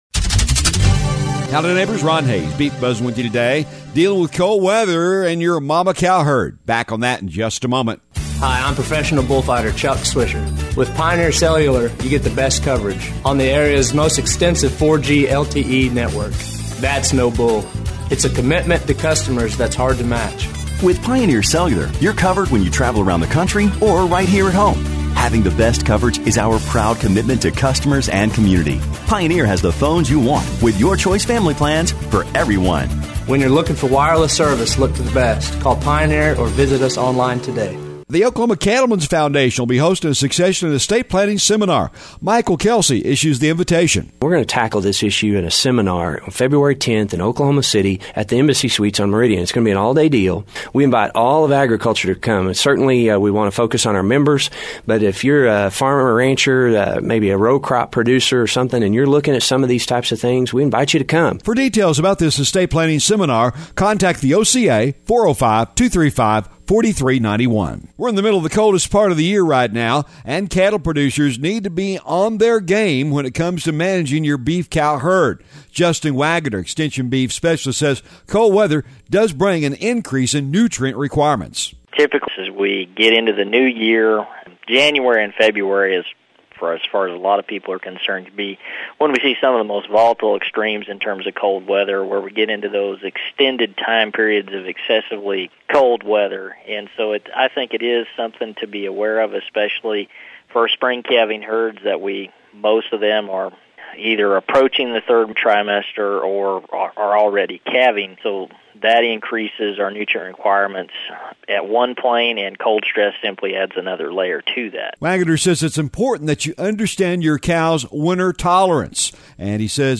The Beef Buzz is a regular feature heard on radio stations around the region on the Radio Oklahoma Network- but is also a regular audio feature found on this website as well.